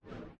sfx
push.ogg